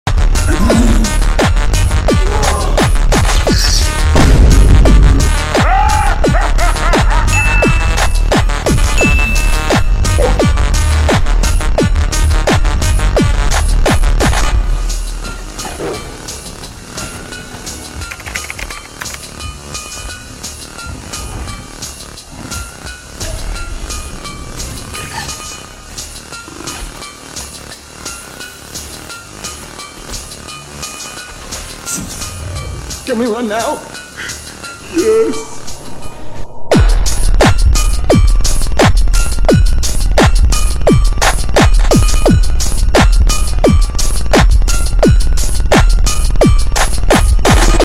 Super Slowed